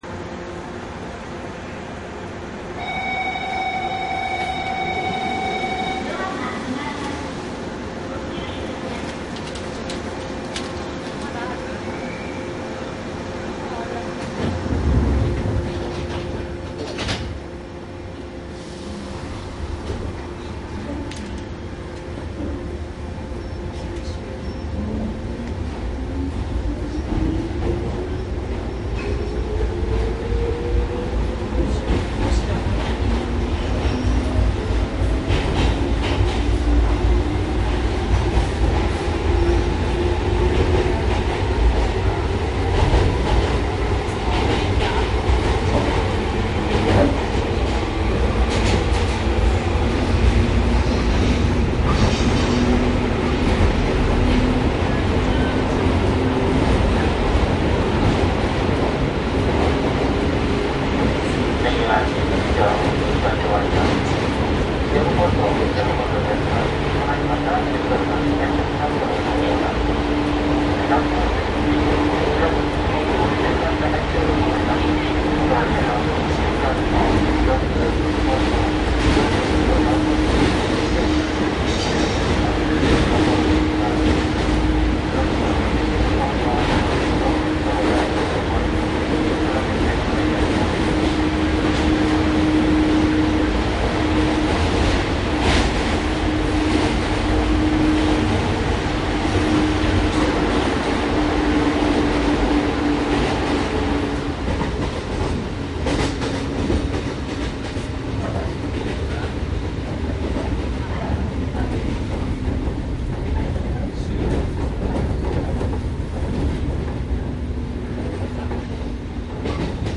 ☆鉄道走行音CD☆東日本113系1000番台・JR総武快速（快速）千葉ゆき
商品説明 113系総武線快速電車の録音です。
収録機材： ソニーTCD－D7 (DAT)
収録マイク ： ソニーＥＣＭ959